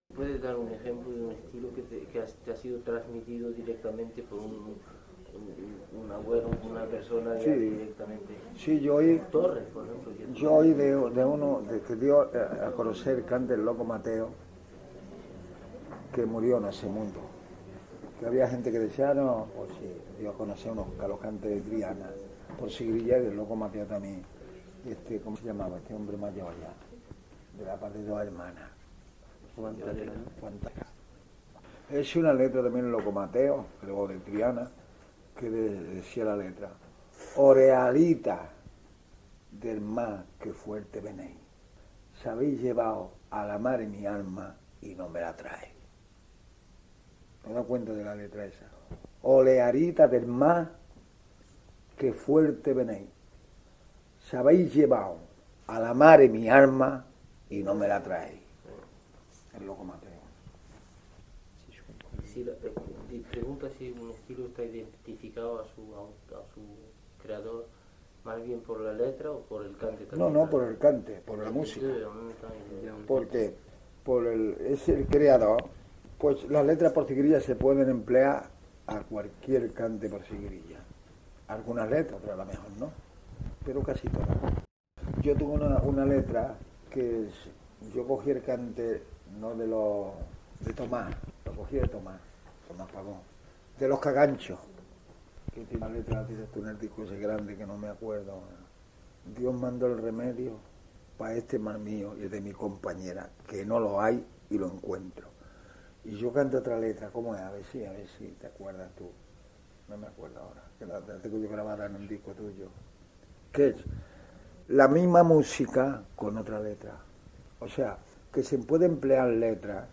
NB : aussi souvent que possible, nous avons coupé les questions au montage. / lo más a menudo posible, hemos suprimido las preguntas.